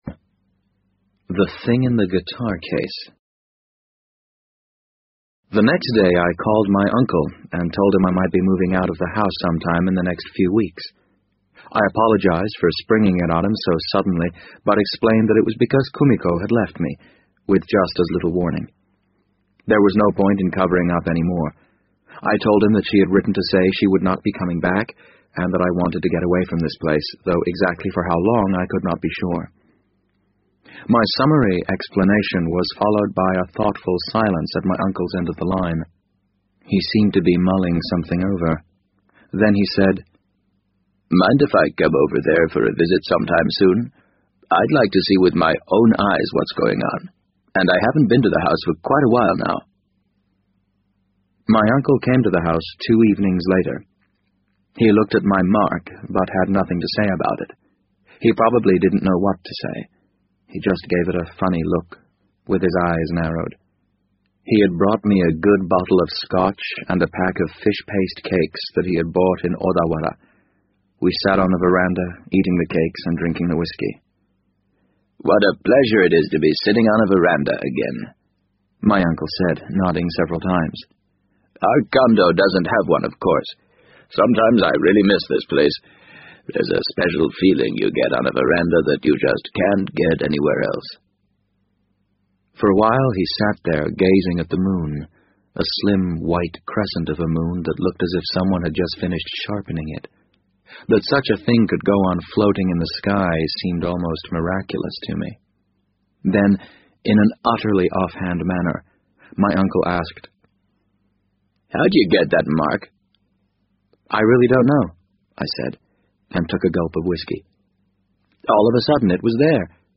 BBC英文广播剧在线听 The Wind Up Bird 008 - 21 听力文件下载—在线英语听力室